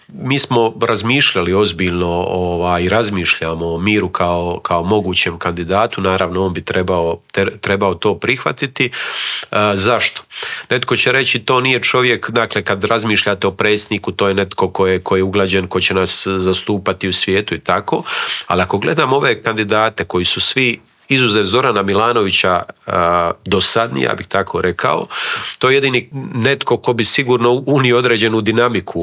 U Intervjuu Media servisa gostovao je upravo Nikola Grmoja i podijelio s nama dojmove sa sjednice.